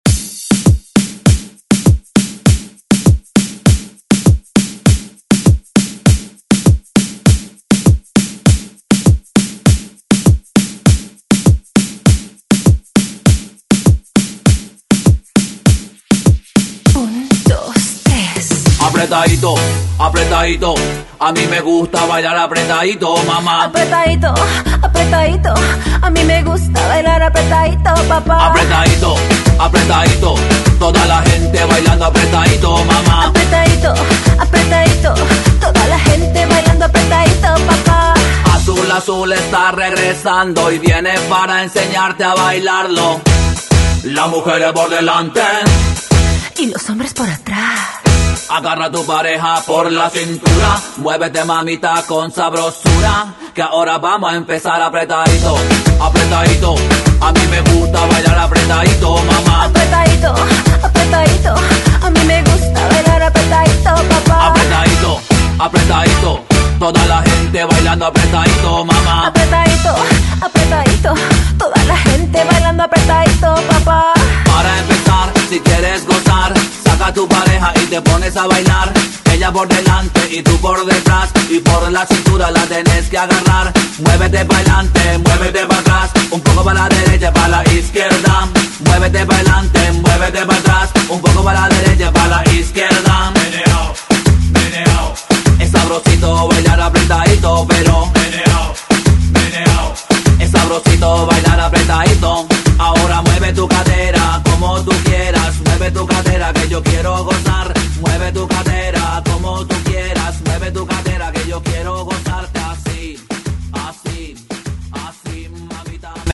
Latin Pop Cumbia Music
100 bpm
BPM: 100 Time